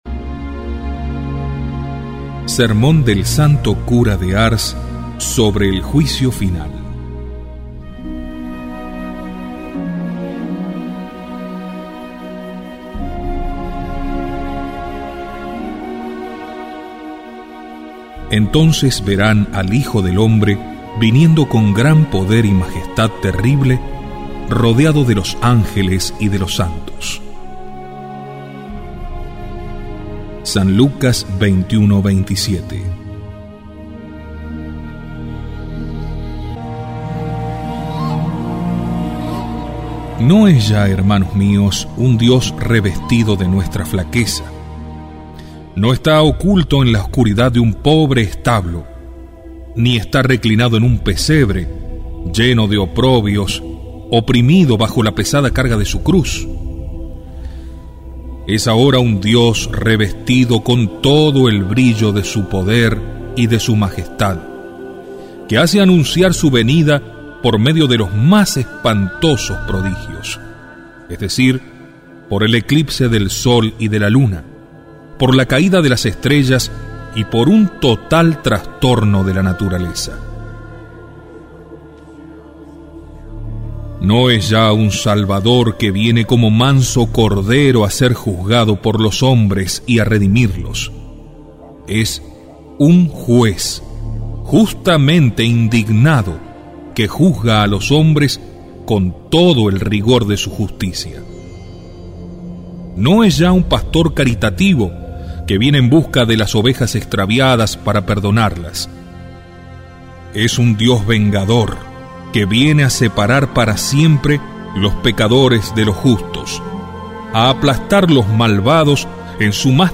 Audio–libros
Sermon-del-Santo-Cura-de-Ars-El Juicio-Final.mp3